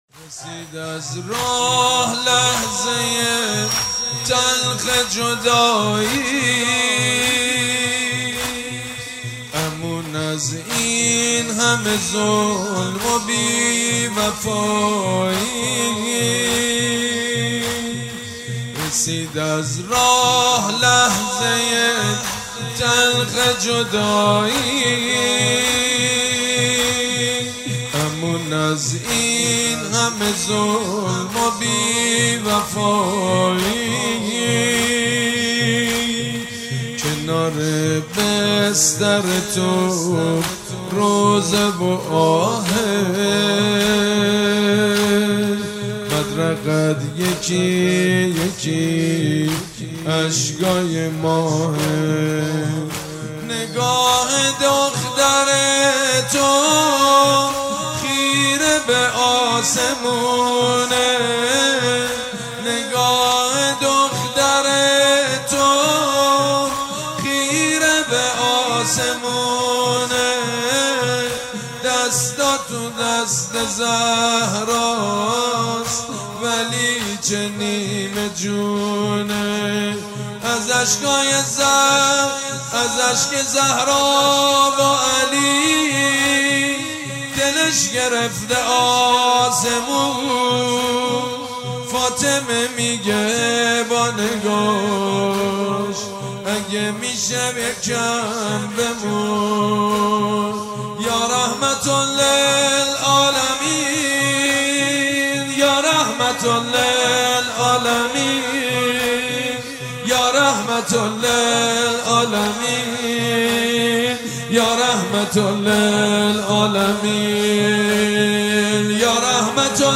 خیمه گاه - عاشقان اهل بیت - سال96- زمینه- رسید از راه لحظه تلخ جدایی- سید مجید بنی فاطمه